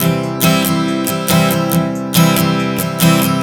Strum 140 C 01.wav